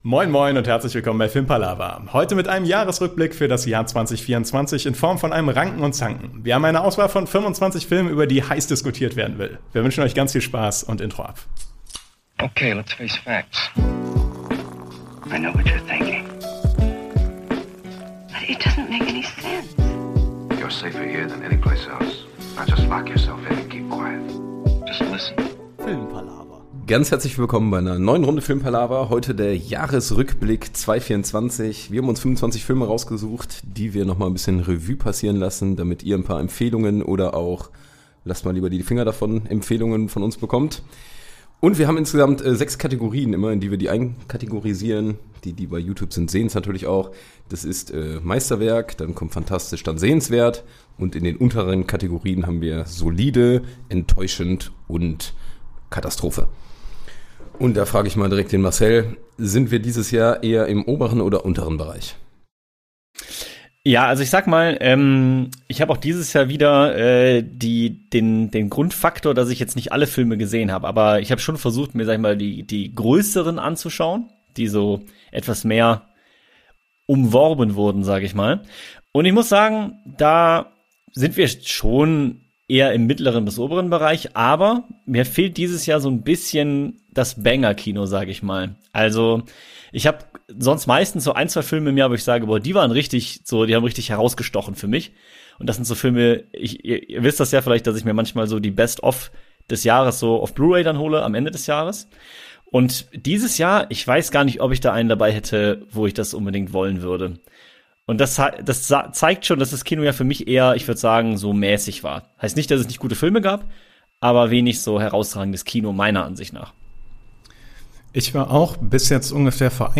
Das Jahr neigt sich dem Ende zu und die Filmpalaver Crew hat sich zusammengesetzt, um die größten Filme des Jahres noch einmal durchzugehen und in das Ranken & Zanken Ranking-System einzuordnen. Es wird viel diskutiert, ein bisschen gezankt und natürlich jede Menge palavert!